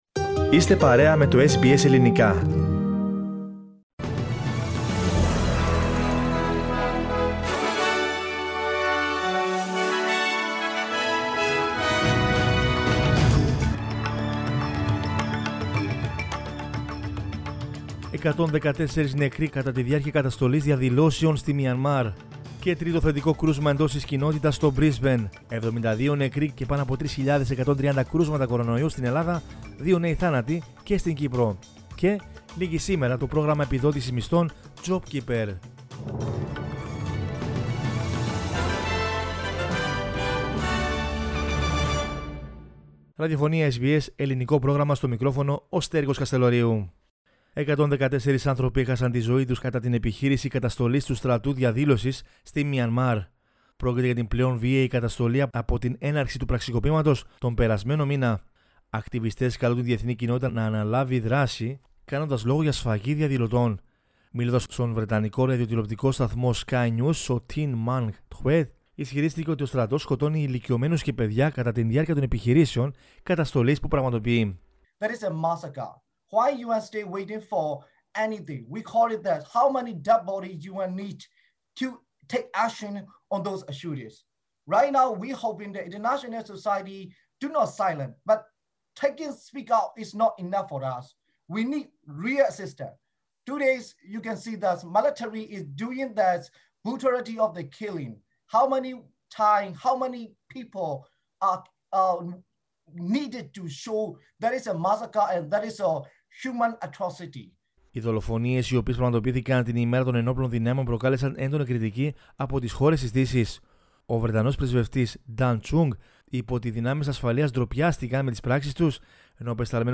News in Greek from Australia, Greece, Cyprus and the world is the news bulletin of Sunday 28 March 2021.